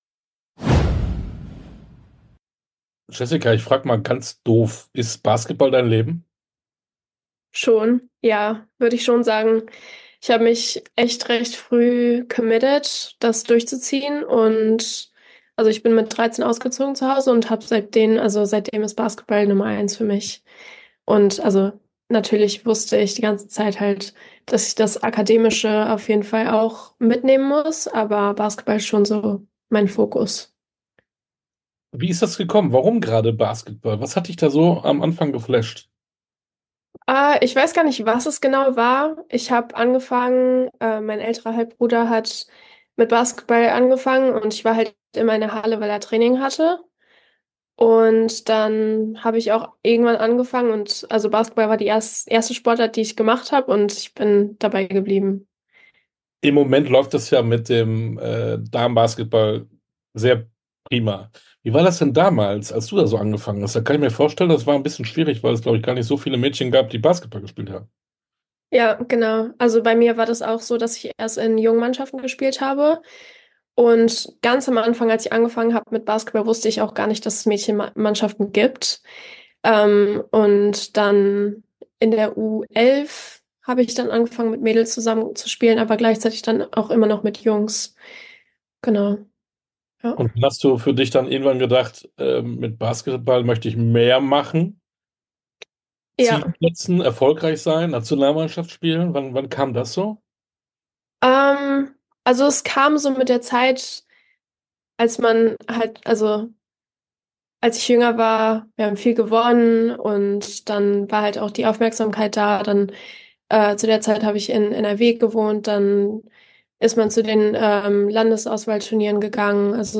Basketball Nationalspielerin ~ Sportstunde - Interviews in voller Länge Podcast